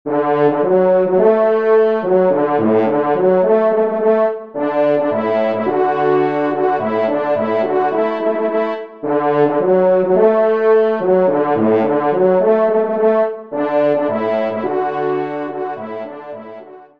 Genre :  Divertissement pour Trompes ou Cors
4e Trompe